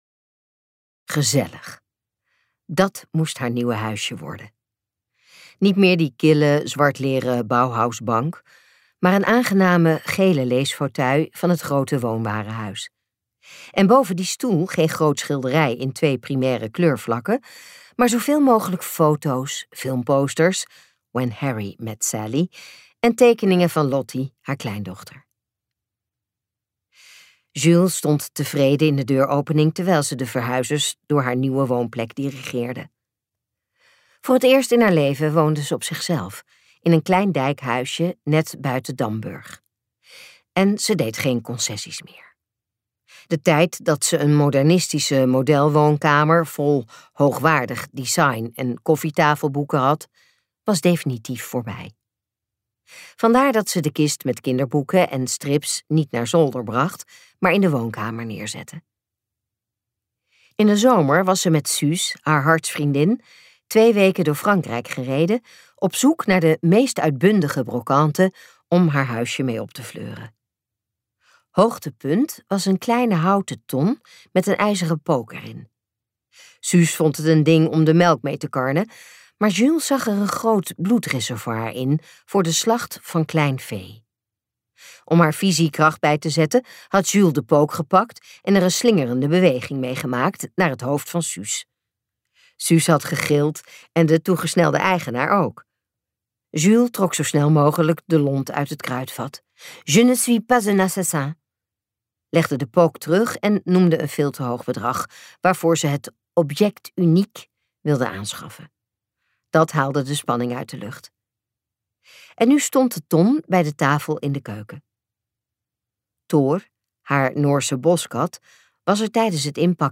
Ambo|Anthos uitgevers - De mosterdmoorden luisterboek